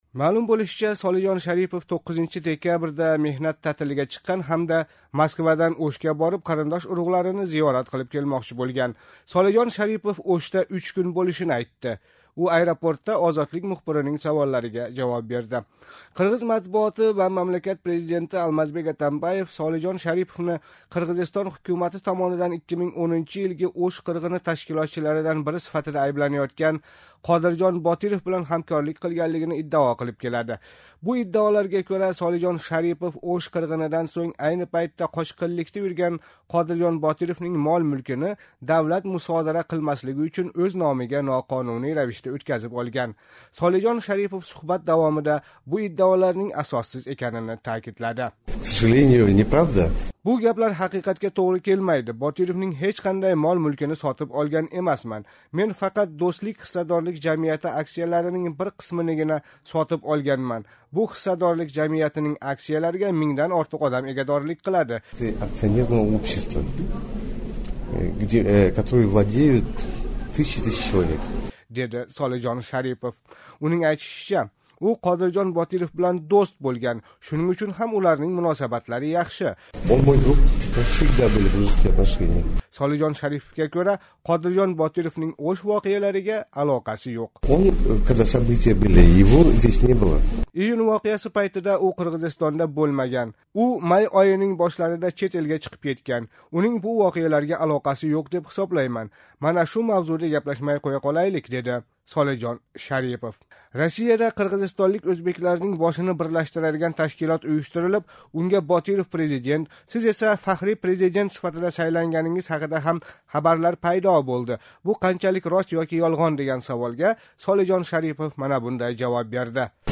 Қирғизистонлик фазогир Солижон Шарипов бу фикрни Бишкекдаги “Манас” аэропортидан Ўшга учиб кетиш арафасида кутилмаганда уни кўриб ва таниб қолган “Озодлик” радиоси қирғиз хизмати мухбири билан қилган қисқача суҳбати пайтида билдирди.